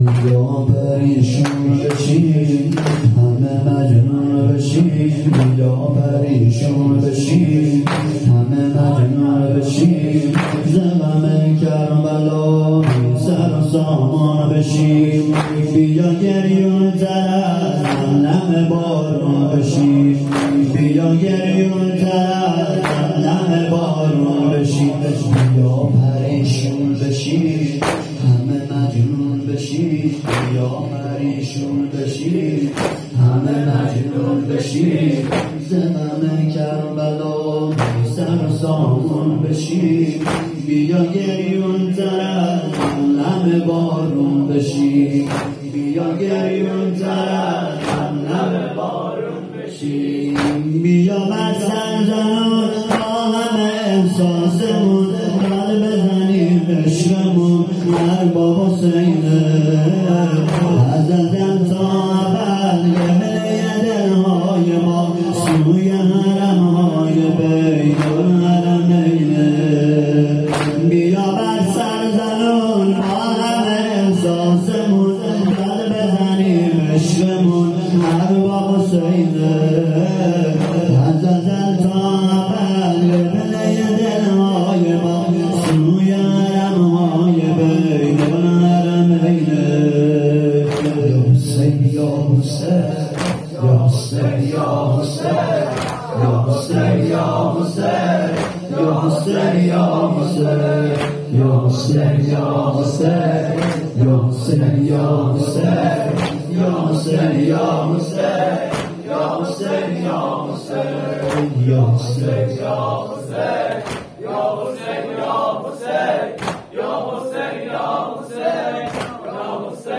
جلسه هفتگی/4آبان1400/ 19ربیع الاول1443